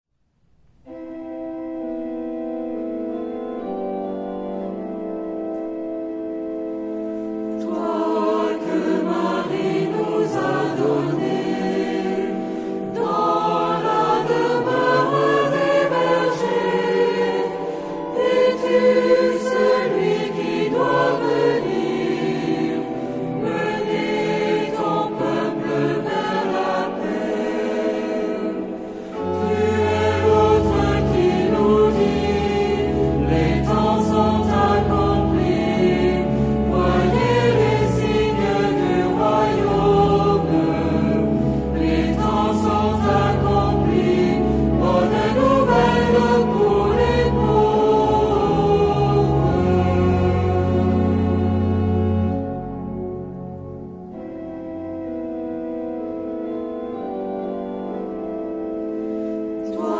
Género/Estilo/Forma: Sagrado ; Himno (sagrado)
Carácter de la pieza : energico
Tipo de formación coral: SATB  (4 voces Coro mixto )
Instrumentos: Organo (1)
Tonalidad : fa sostenido menor ; la menor